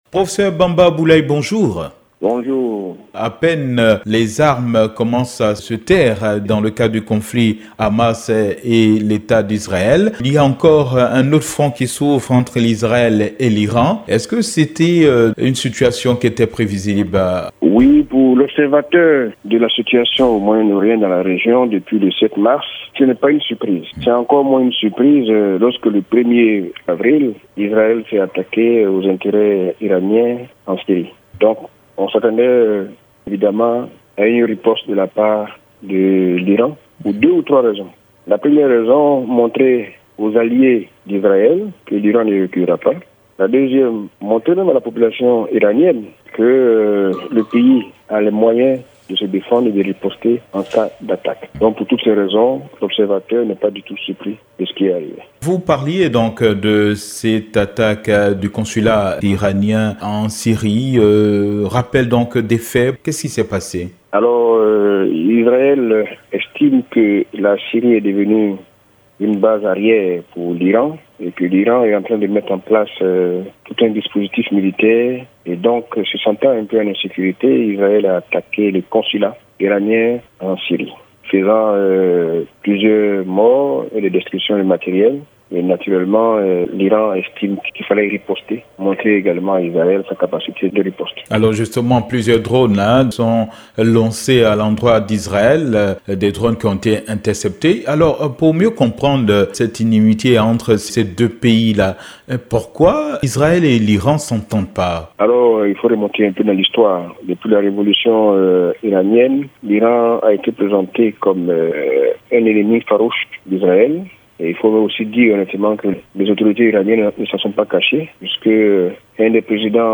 Invité de la Rédaction